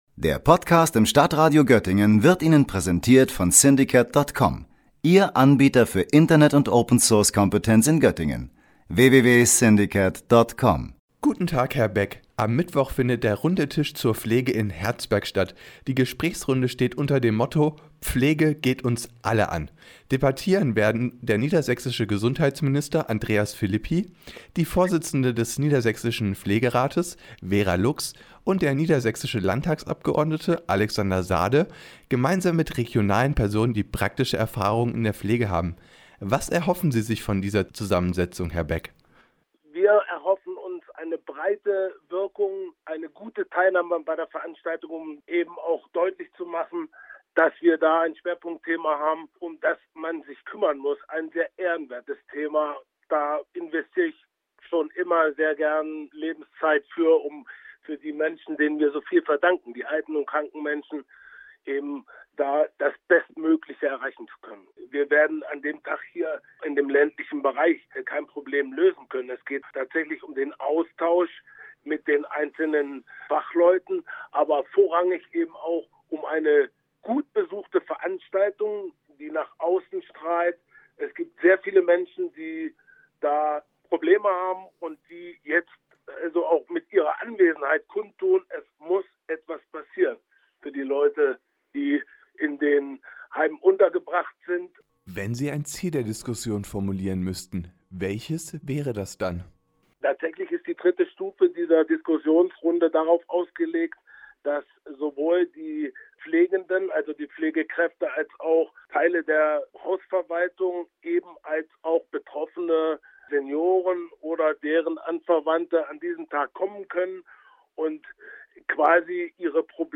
Er interviewt Thomas Beck, den Bürgermeister von Lonau.